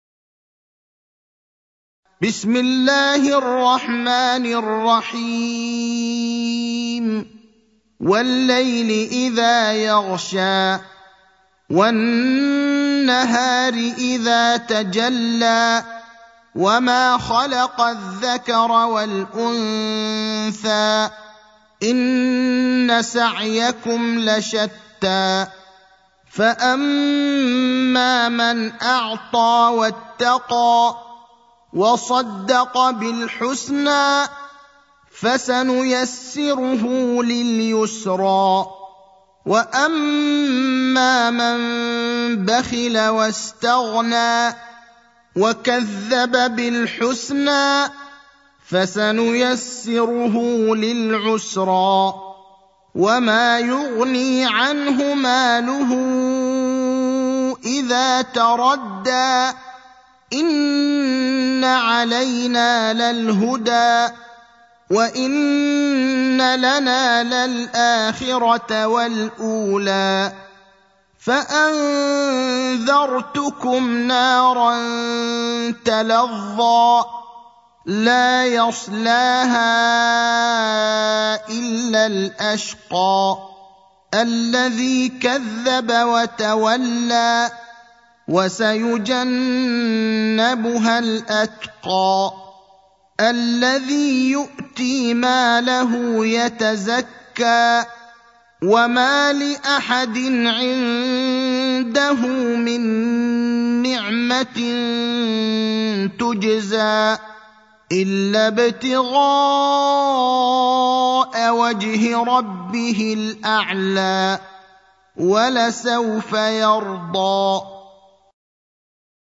المكان: المسجد النبوي الشيخ: فضيلة الشيخ إبراهيم الأخضر فضيلة الشيخ إبراهيم الأخضر الليل (92)